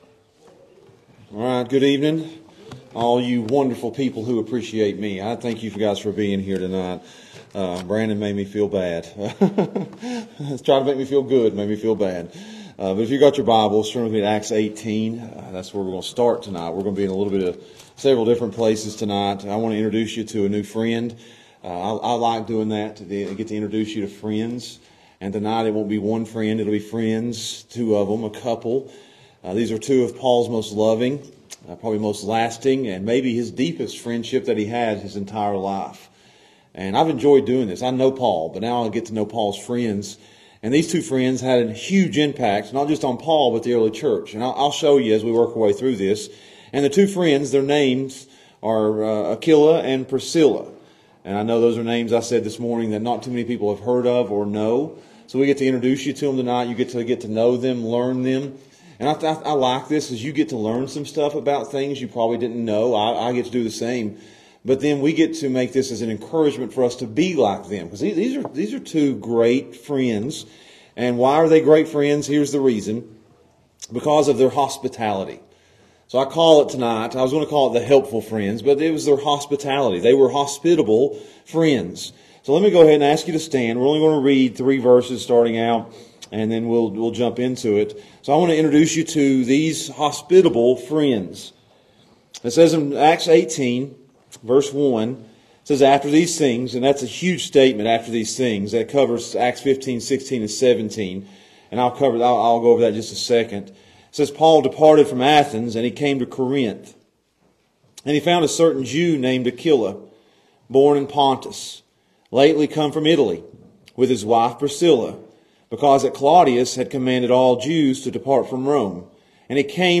The Hospitable Friends | SermonAudio Broadcaster is Live View the Live Stream Share this sermon Disabled by adblocker Copy URL Copied!